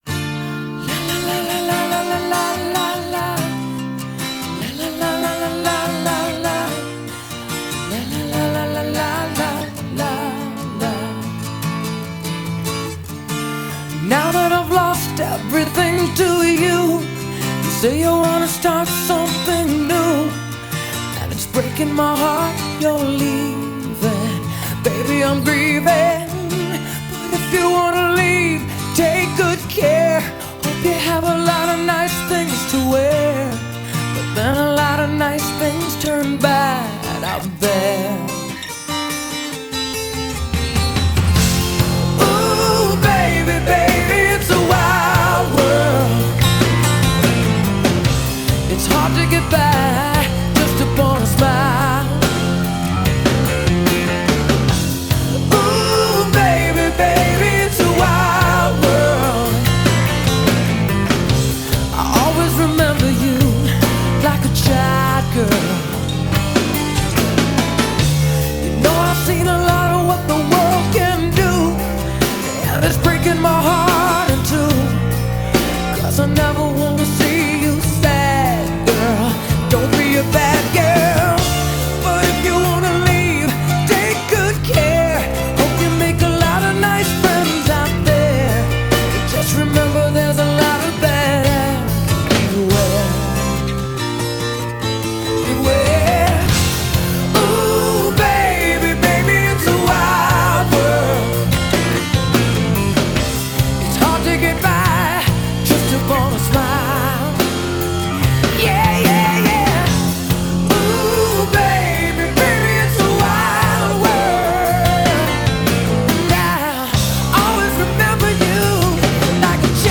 BPM141-147
Audio QualityMusic Cut
it sounds better in F